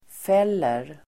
Uttal: [f'el:er]